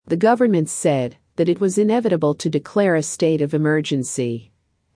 【ややスロー・スピード】
答案の分析が済んだら、ネイティブ音声を完全にコピーするつもりで音読を反復してくださいね。